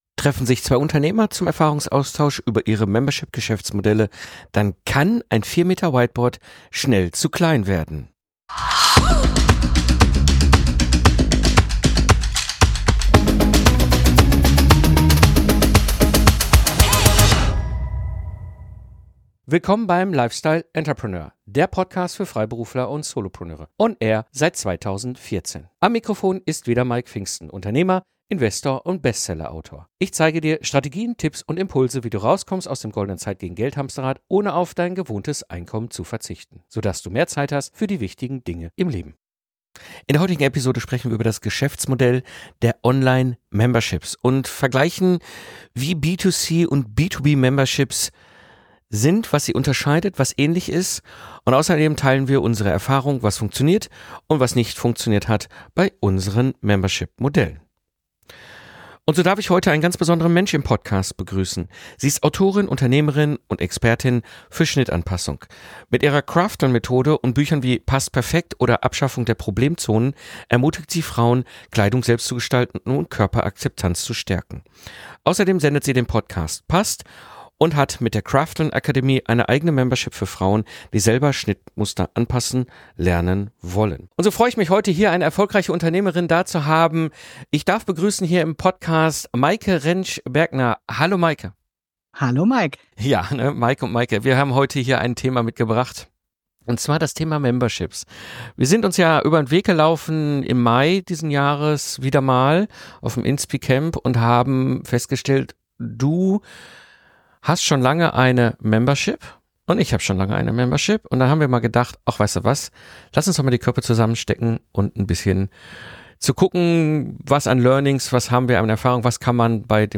Последний - LE298 Membership als Geschäftsmodell, was funktioniert und was nicht? Interview